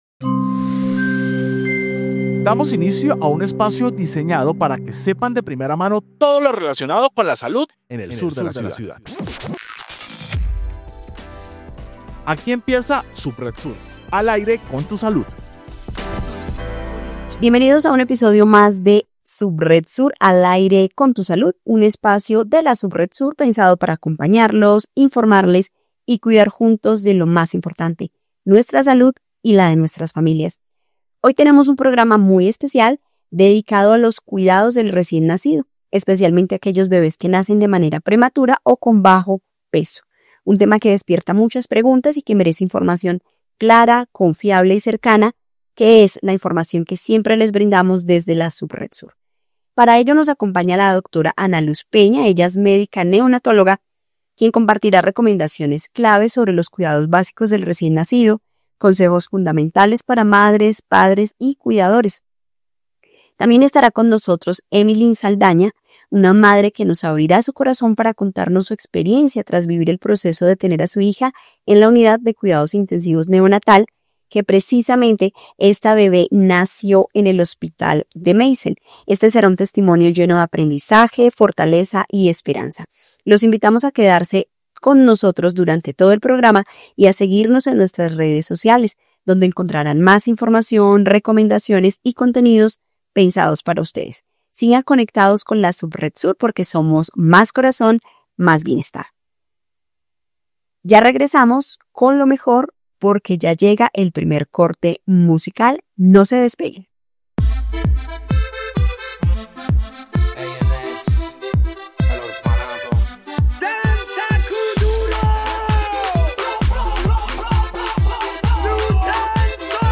Programa Radial Subred Sur: Al Aire con la Salud